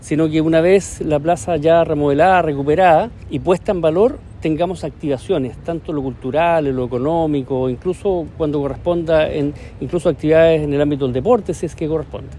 Además, Patricio Rojas, jefe Regional de la Subdere, señaló que este será un espacio de esparcimiento y recreación, sobre todo para los jóvenes.